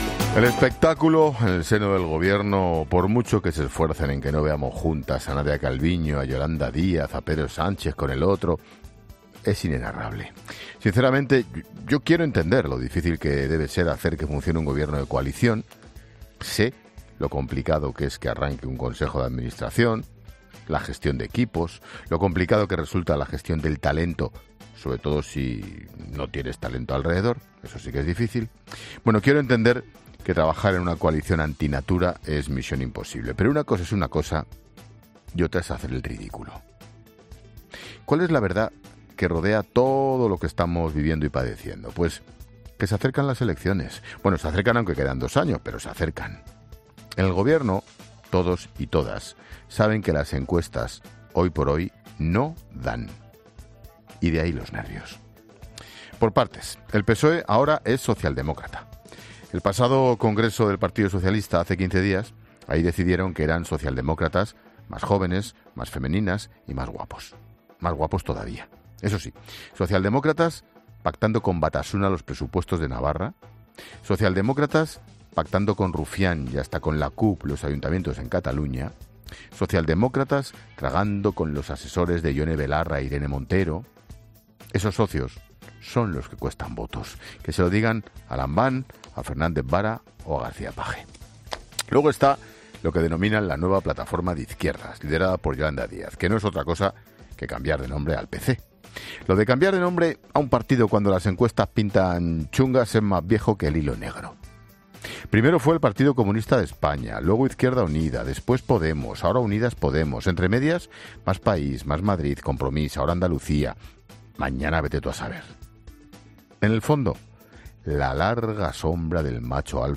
El director de 'La Linterna', Ángel Expósito, reflexiona sobre múltiples asuntos de actualidad en su monólogo de este jueves